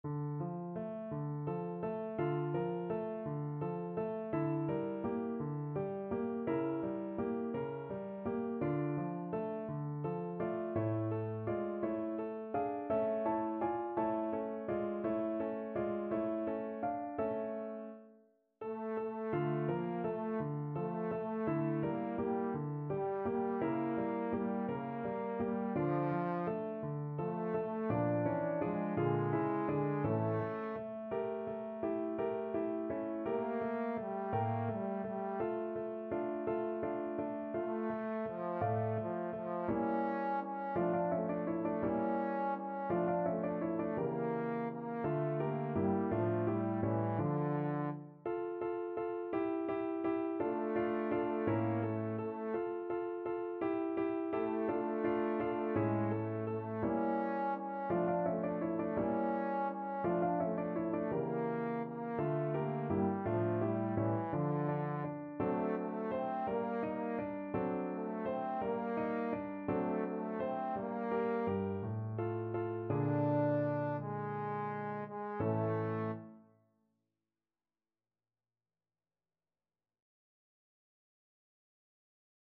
Classical Mozart, Wolfgang Amadeus L'ho perduta me meschina from from Le Nozze di Figaro Trombone version
6/8 (View more 6/8 Music)
. = 56 Andante
D minor (Sounding Pitch) (View more D minor Music for Trombone )
Classical (View more Classical Trombone Music)